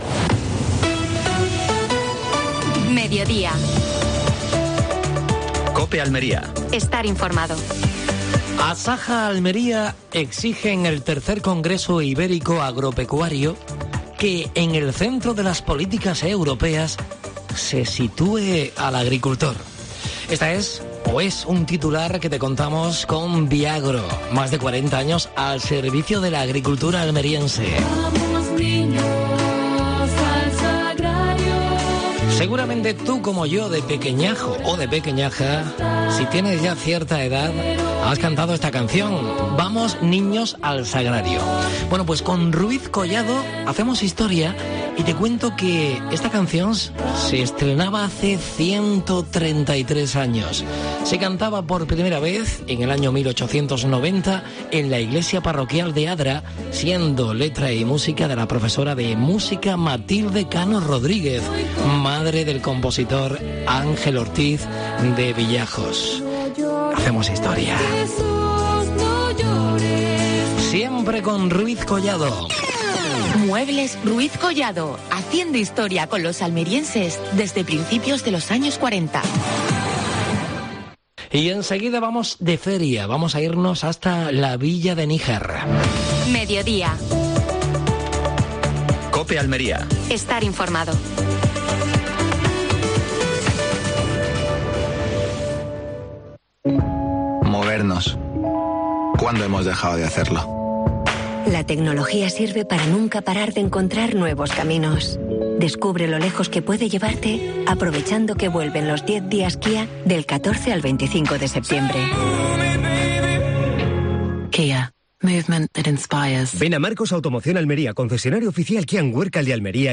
AUDIO: Programa especial con motivo de la Feria de Níjar. Entrevista a José Francisco Garrido (alcade de Níjar).